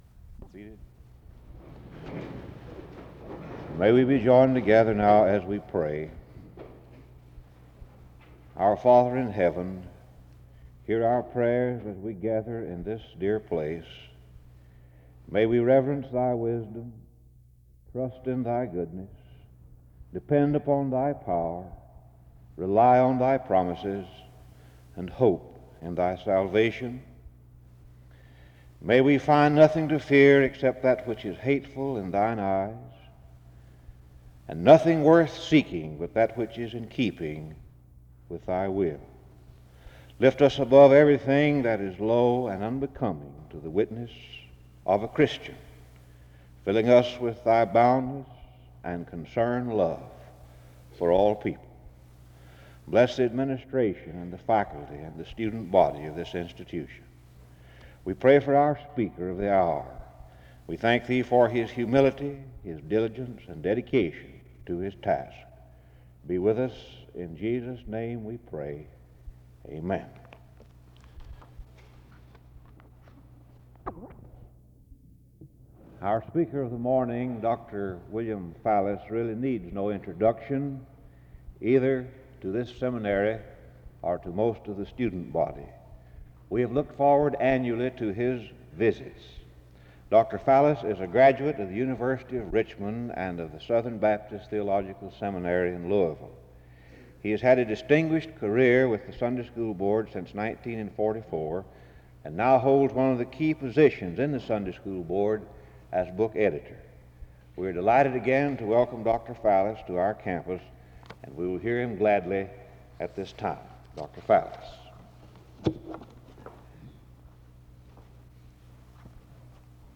The service starts with a prayer from 0:00-1:10. An introduction to the speaker is given from 1:16-1:54.